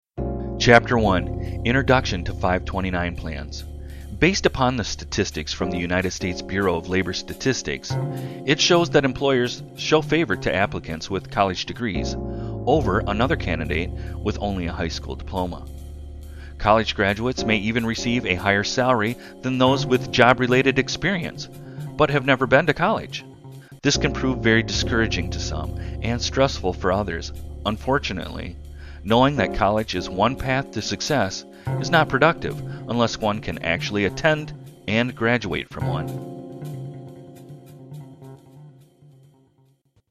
College Savings Plans Audio book